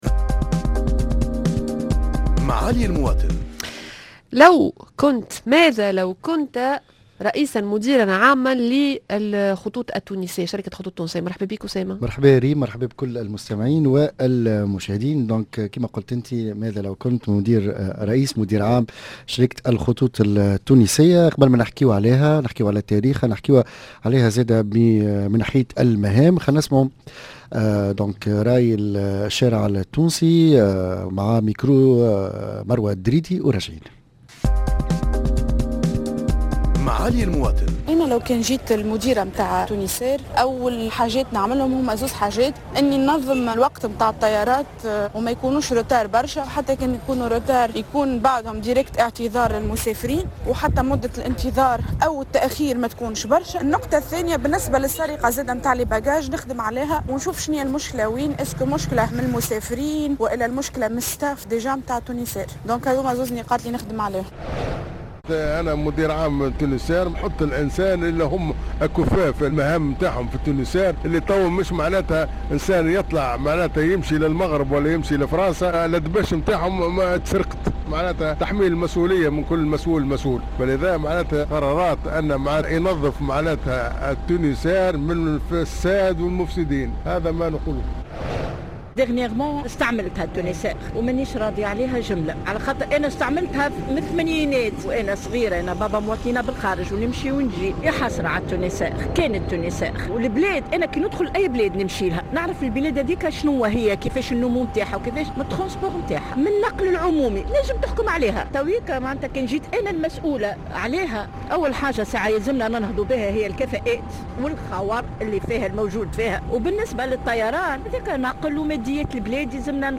Micro trottoir